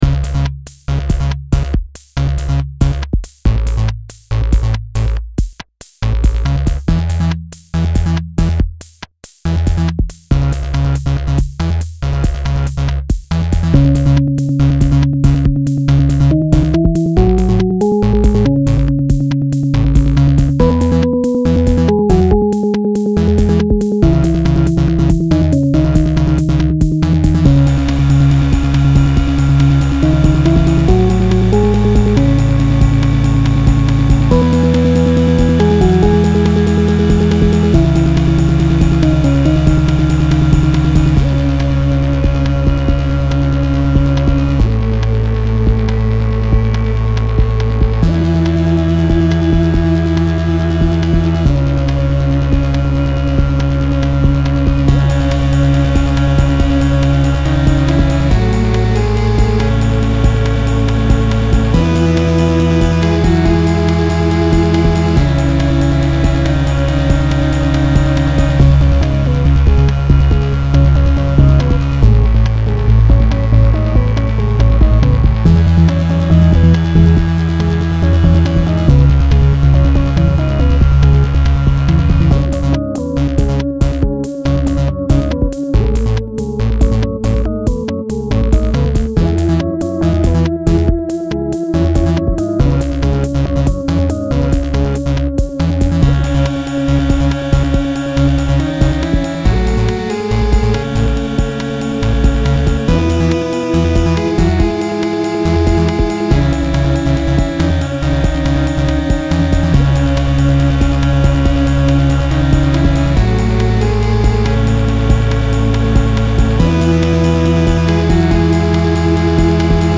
Filed under: Remix | Comments (1)
My only problem with chiptune kinds of music is that it doesn't have enough OOOMPH, maybe layer a kick with some more punch in there so the rhythm can be "felt" a bit more.
The main melody is pretty catchy, but it does seem to go on a bit too long.